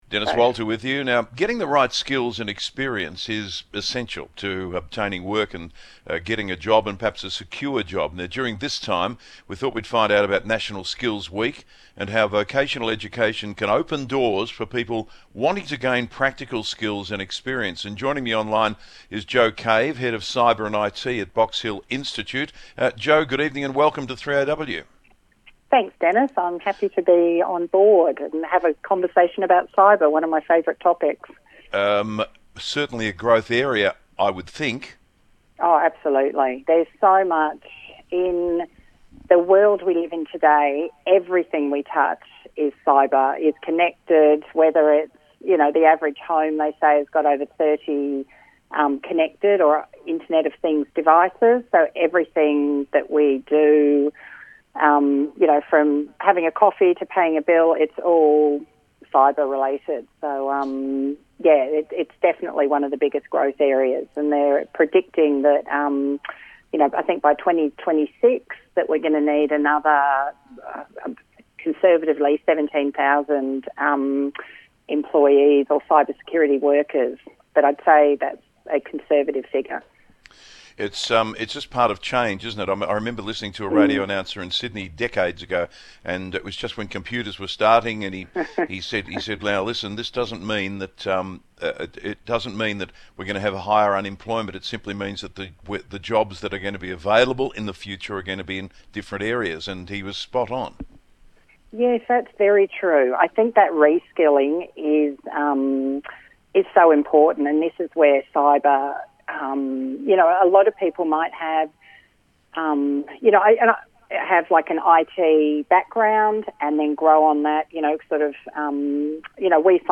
Cyber Security- Radio Interview